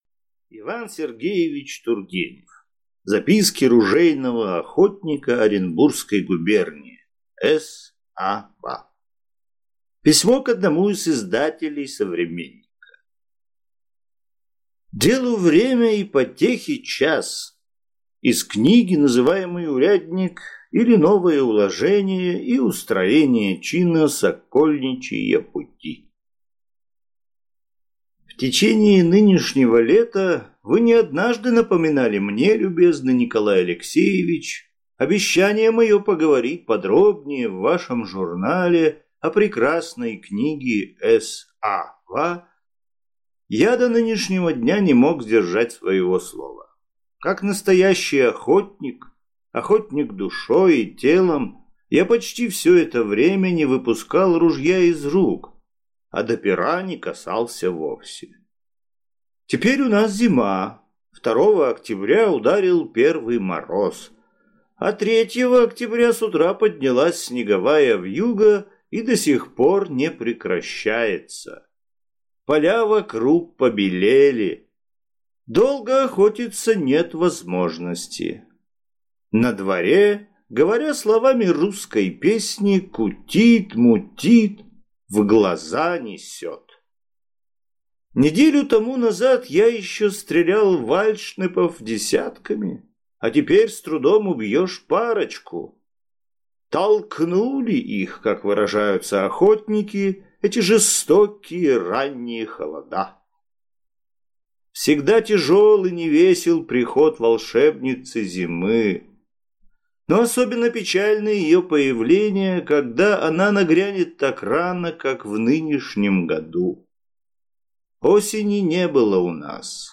Аудиокнига Записки ружейного охотника Оренбургской губернии. С. А-ва.